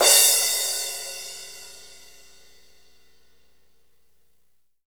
CYM A C CR0L.wav